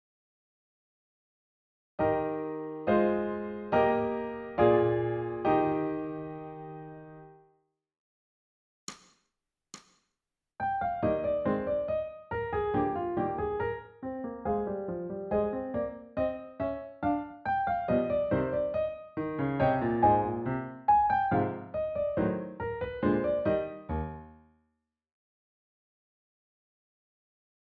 ソルフェージュ 聴音: 2-m-03